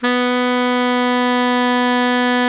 BARI  MF B 2.wav